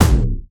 poly_explosion_dark02.wav